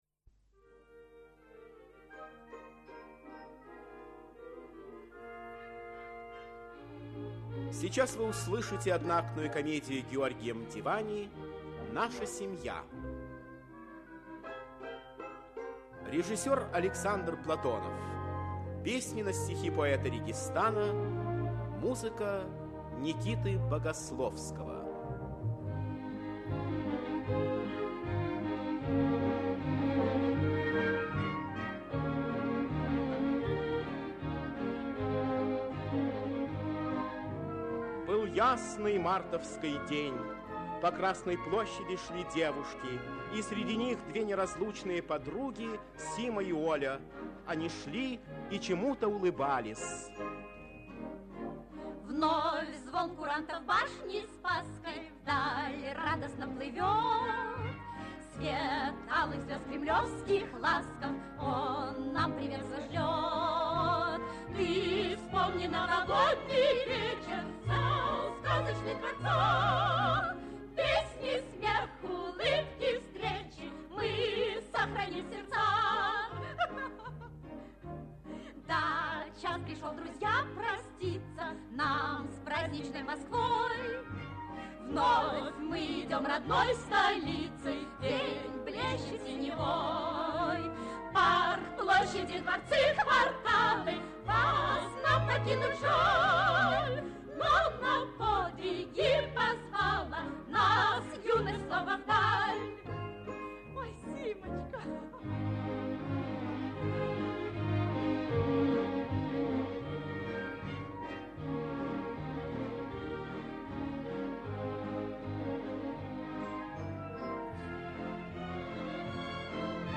Аудиокнига Наша семья | Библиотека аудиокниг
Aудиокнига Наша семья Автор Георгий Давидович Мдивани Читает аудиокнигу Актерский коллектив.